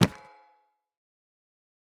pickup_enchanted1.ogg